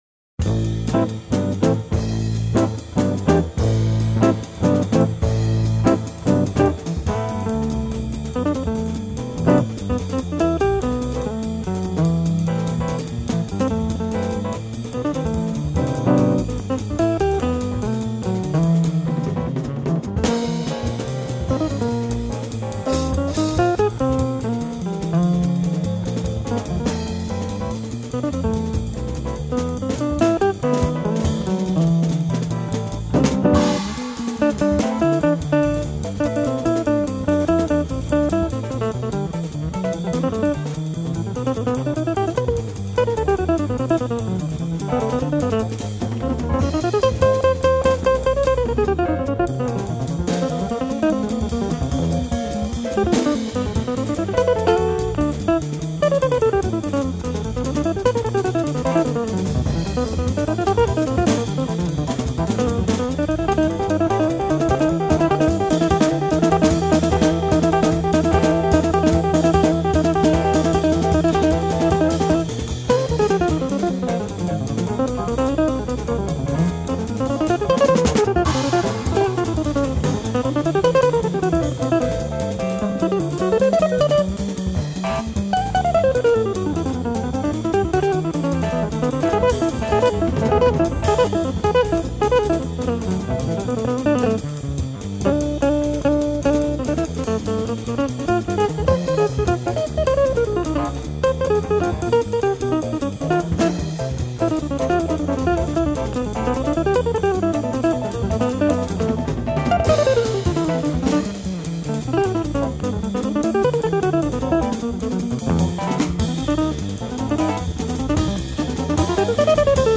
jazz guitar